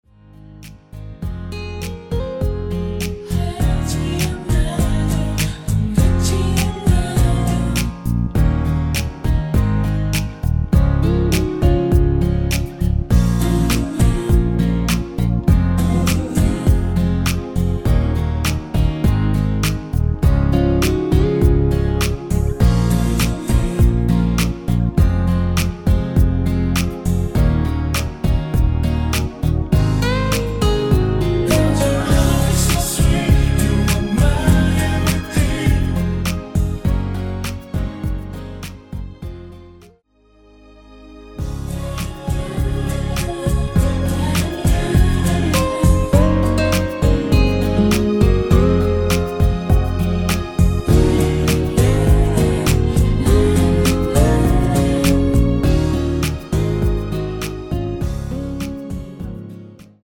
(-2) 내린 코러스만 포함된 MR 입니다.(미리듣기 참조)
Db
앞부분30초, 뒷부분30초씩 편집해서 올려 드리고 있습니다.
중간에 음이 끈어지고 다시 나오는 이유는